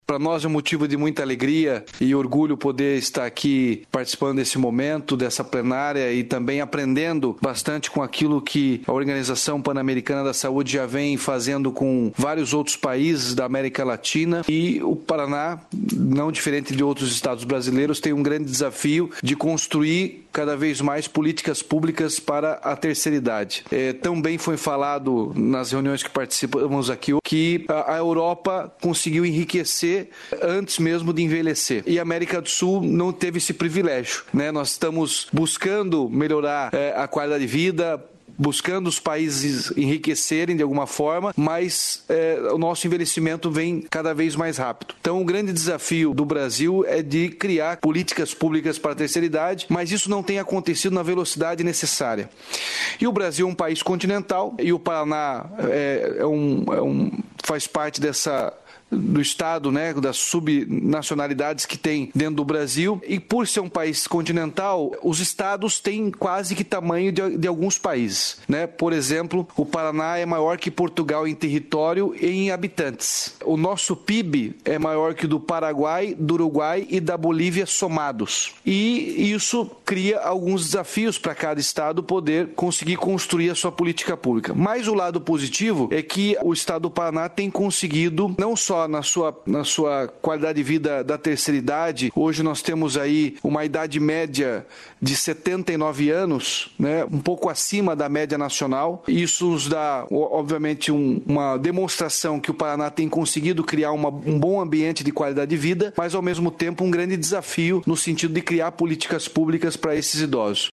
Sonora do governador Ratinho Junior sobre o prêmio recebido em Washington